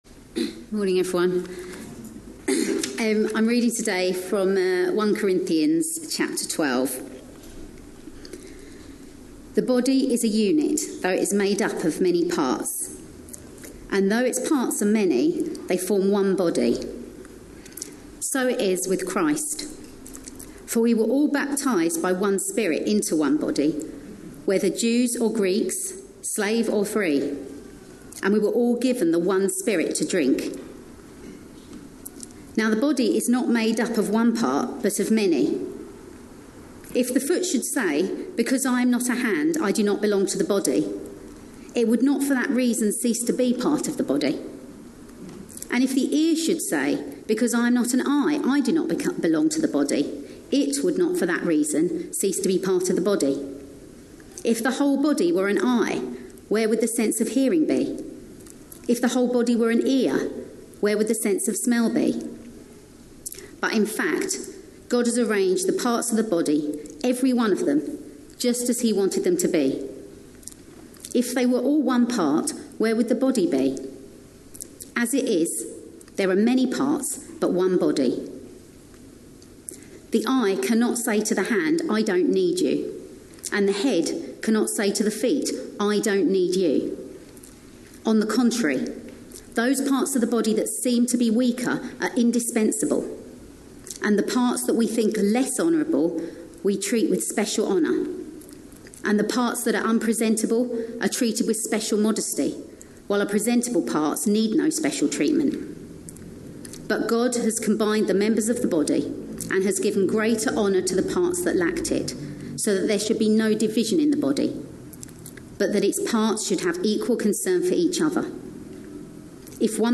A sermon preached on 12th May, 2019.